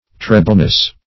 Search Result for " trebleness" : The Collaborative International Dictionary of English v.0.48: Trebleness \Tre"ble*ness\, n. The quality or state of being treble; as, the trebleness of tones.